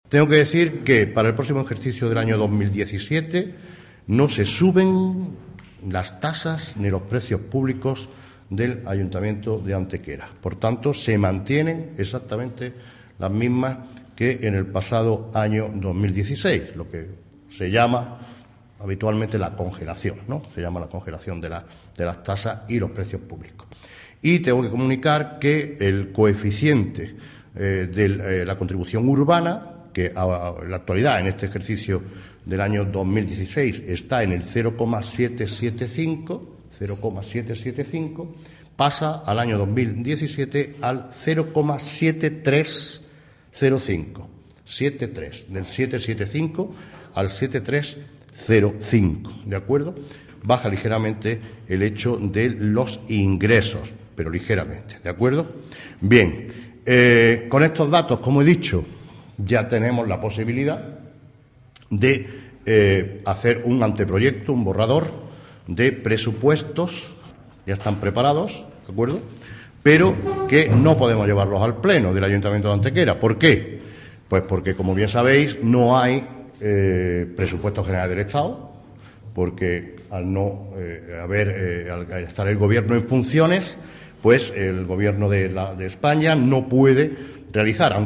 El alcalde de Antequera, Manolo Barón, ha confirmado esta mañana en rueda de prensa que el anteproyecto de los Presupuestos Municipales para el ejercicio 2017 ya está hecho.
Cortes de voz